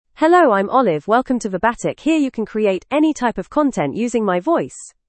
FemaleEnglish (United Kingdom)
Olive is a female AI voice for English (United Kingdom).
Voice sample
Listen to Olive's female English voice.
Olive delivers clear pronunciation with authentic United Kingdom English intonation, making your content sound professionally produced.